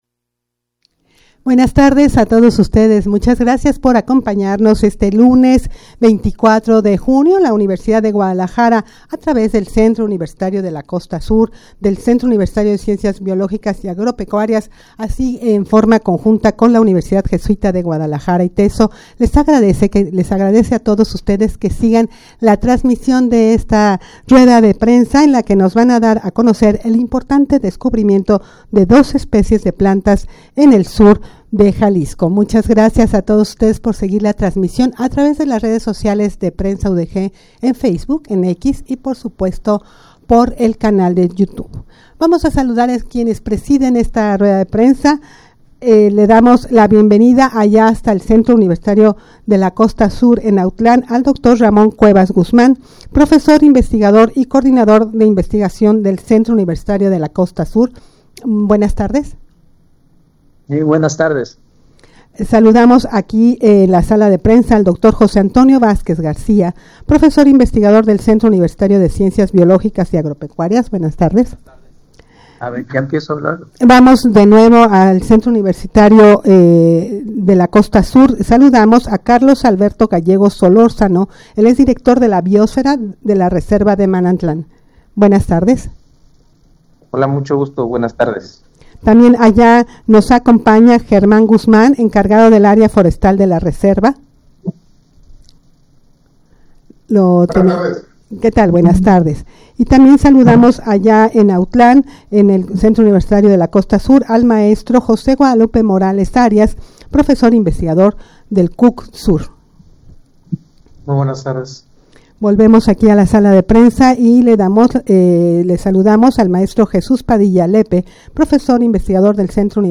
Audio de la Rueda de Prensa
rueda-de-prensa-para-dar-a-conocer-el-importante-descubrimiento-de-dos-especies-de-plantas-del-sur-de-jalisco.mp3